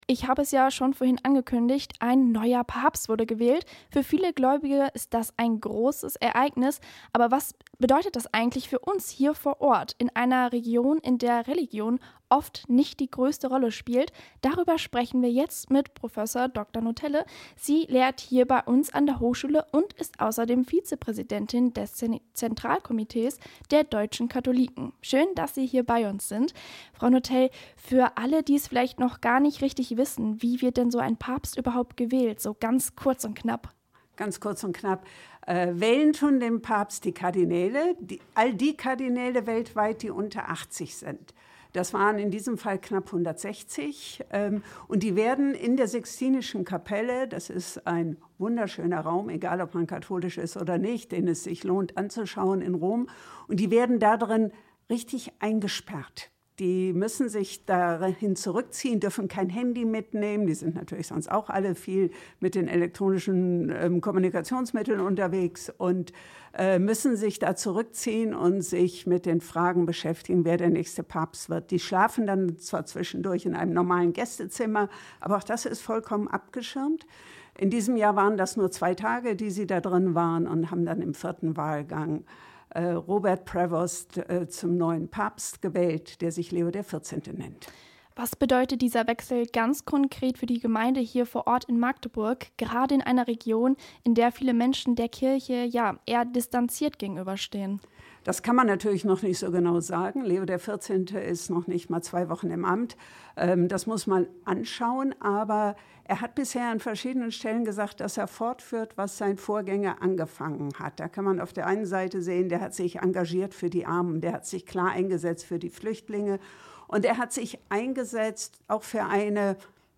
Interview zu Papst Leo XIV.
Interview-Papsat.mp3